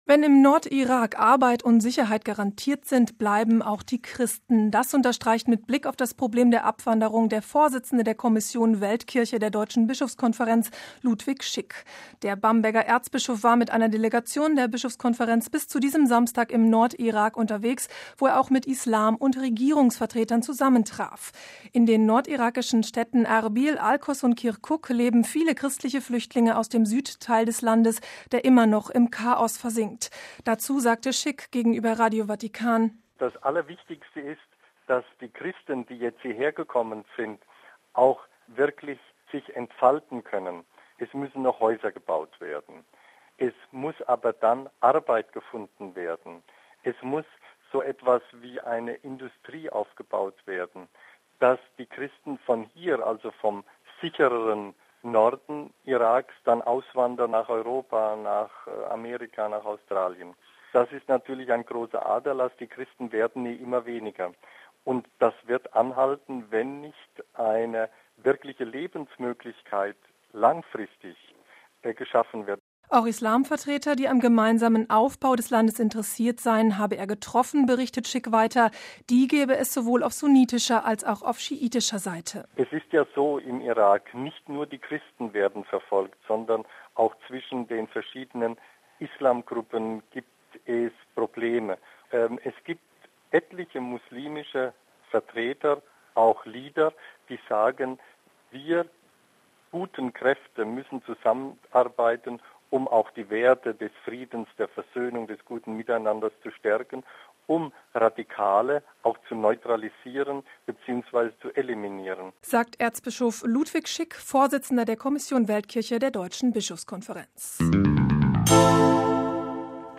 Dazu sagte Schick gegenüber Radio Vatikan: